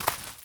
2scroll.wav